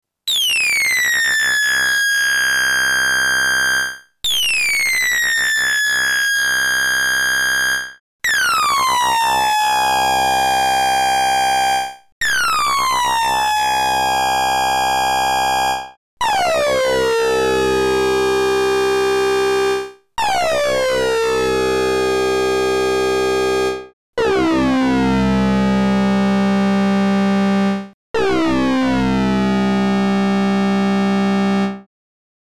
Вот ваш железный обер супротив артурии. Ничего особо не подгонял, за пару минут пару ручек двинул и уже попадание на 99 процентов.